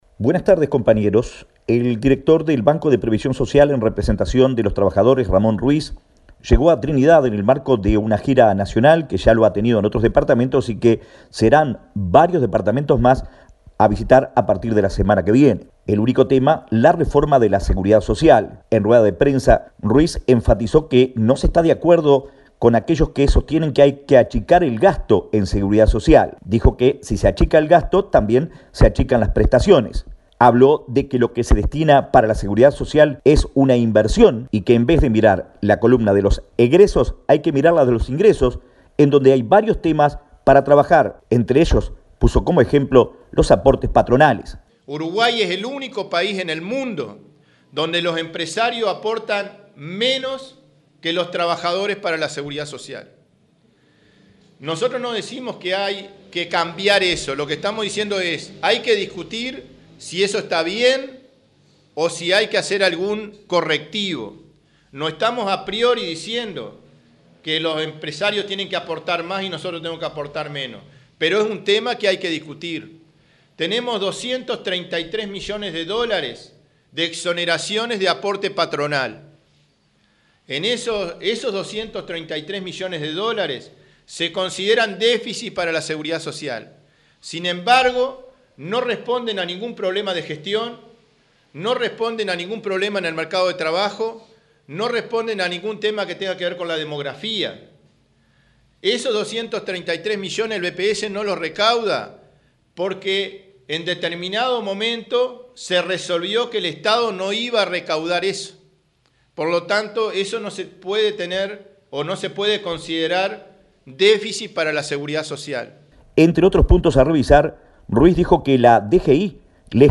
El director del BPS en representación de los Trabajadores, Ramón Ruíz, dijo en Trinidad, hablando de la reforma de la Seguridad Social, que hay que trabajar en los ingresos que tiene el Banco y no en el gasto, porque "si achicamos el gasto, achicamos las prestaciones".
Informe del corresponsal